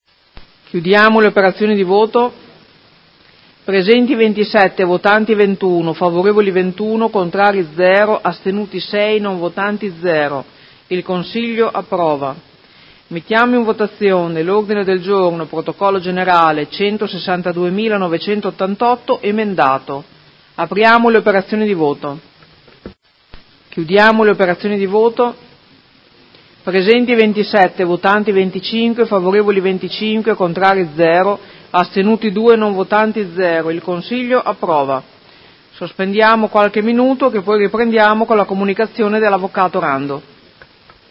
Presidentessa — Sito Audio Consiglio Comunale
Seduta del 10/01/2019 Mette ai voti emendamento n. 10840.